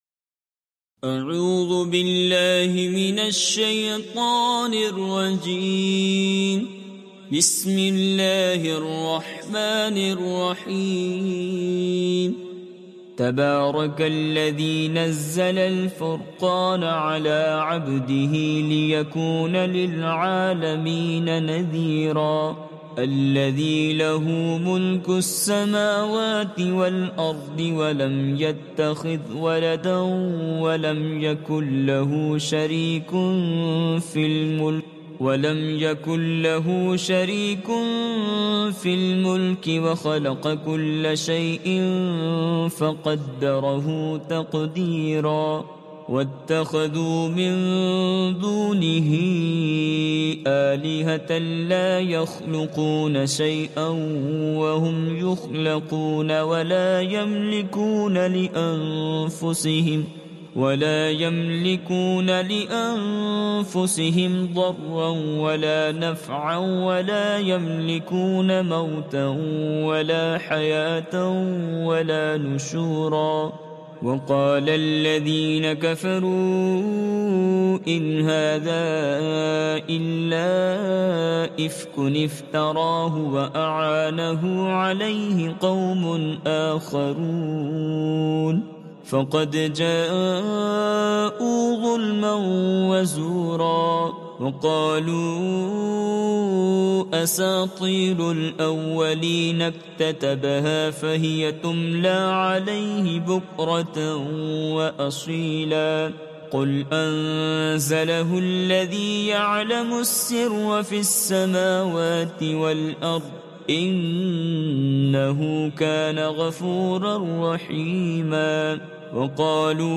From this page, you can read Surah Furqan online listen to its mp3 audio, download recitations, and download PDF to read it offline with Urdu and English translations for better understanding.